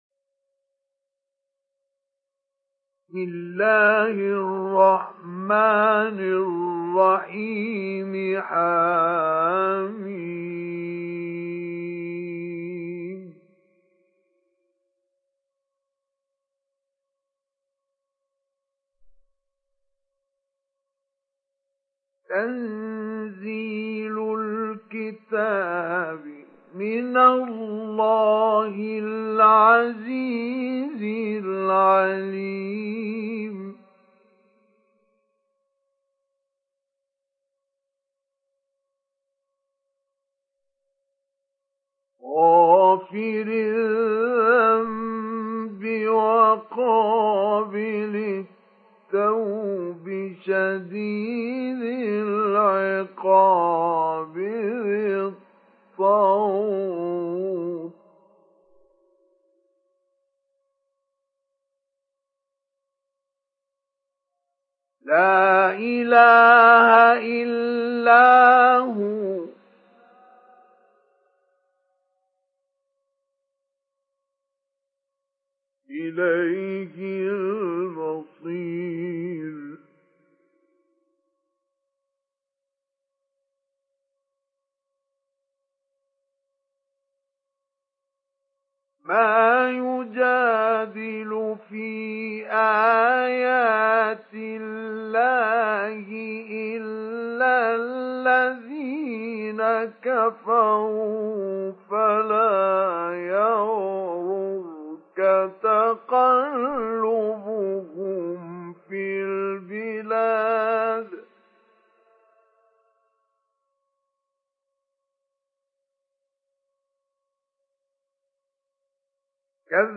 Download Surat Ghafir Mustafa Ismail Mujawwad